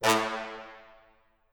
Fat_Horn_7.wav